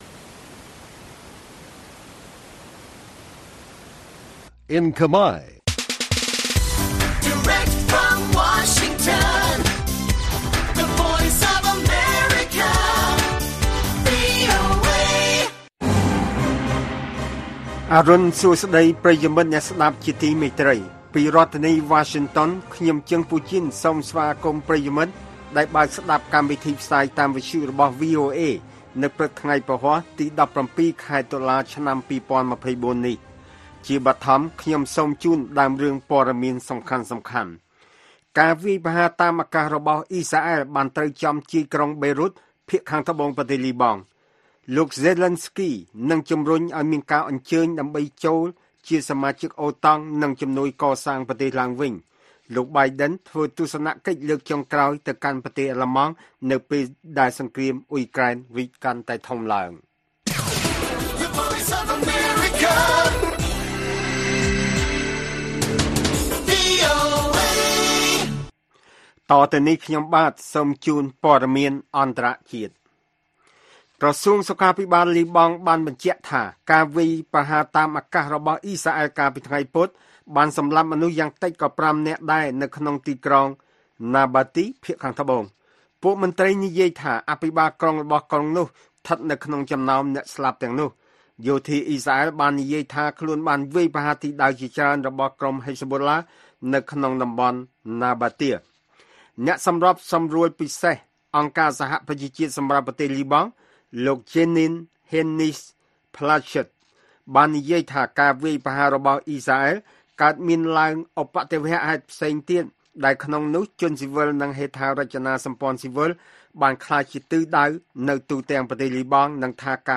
ព័ត៌មានពេលព្រឹក១៧ តុលា៖ ពលរដ្ឋនៅអ៊ុយក្រែនវិលវល់និងស្រពិចស្រពិល ខណៈអាមេរិកជិតបោះឆ្នោតប្រធានាធិបតី